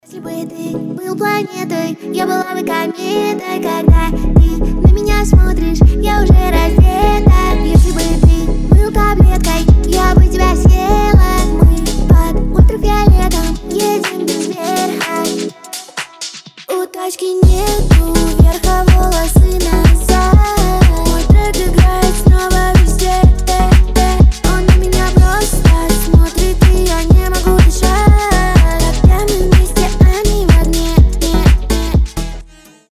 • Качество: 320, Stereo
красивый женский голос